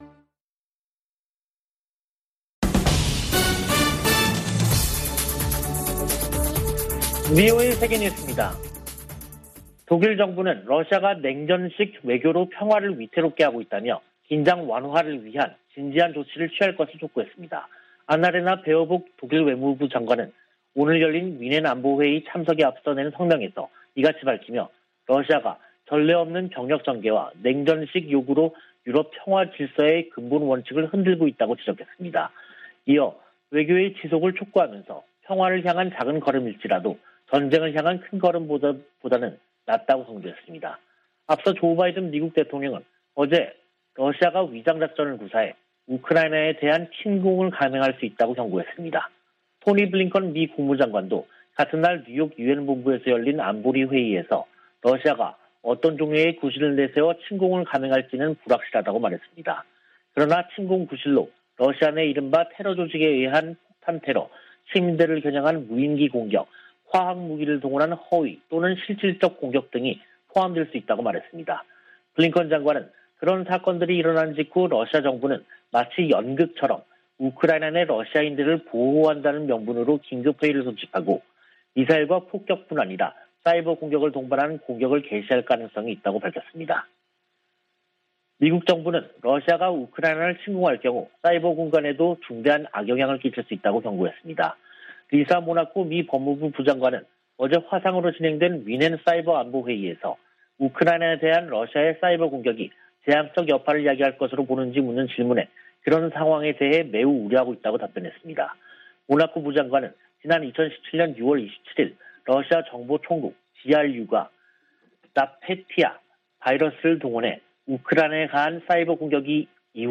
VOA 한국어 간판 뉴스 프로그램 '뉴스 투데이', 2022년 2월 18일 3부 방송입니다. 미 국무부 동아태 차관보가 미국, 한국, 일본의 최우선 과제로 북한 핵·미사일 위협 대응을 꼽았습니다. 해리 해리스 전 주한 미국대사는 대화를 위해 북한 위협 대응 능력을 희생하면 안된다고 강조했습니다. 북한이 가상화폐 자금에 고도화된 세탁 수법을 이용하고 있지만 단속이 불가능한 것은 아니라고 전문가들이 말했습니다.